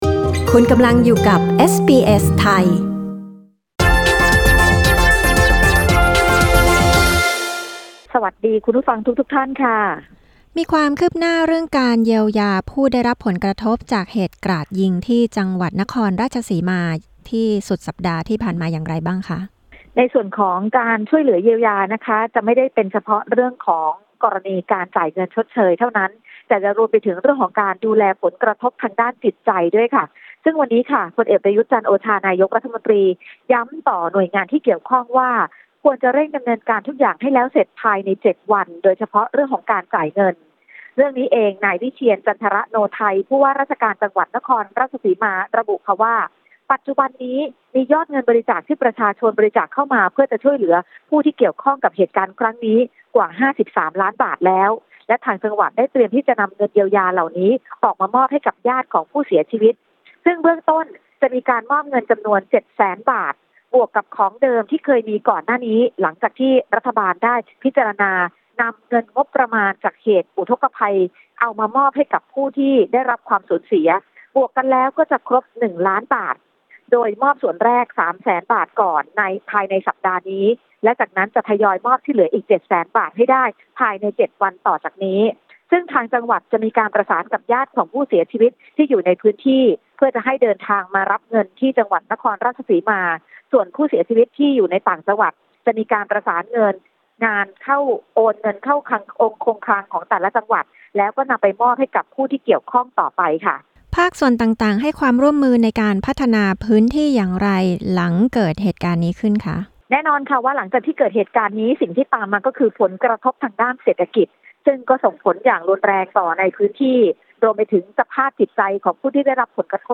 กดปุ่ม 🔊 ด้านบนเพื่อฟังรายงานข่าวเรื่องนี้